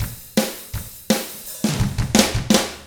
164ROCK F3-L.wav